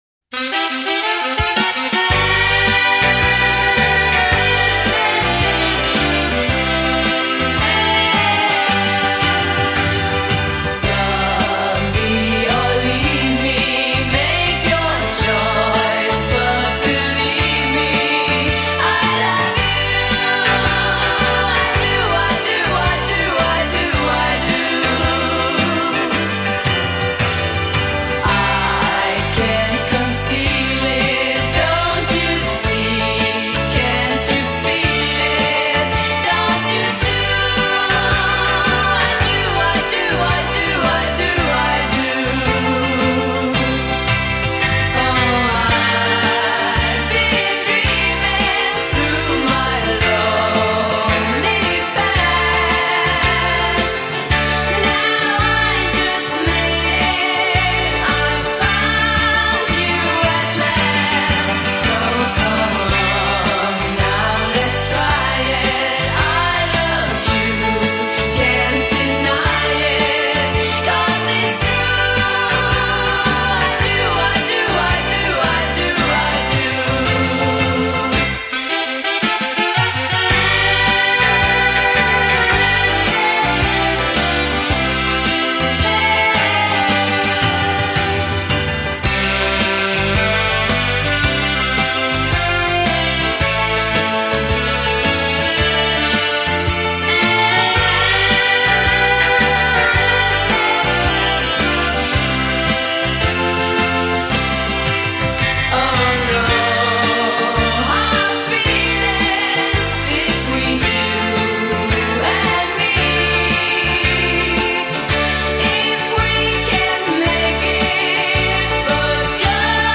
09 Foxtrot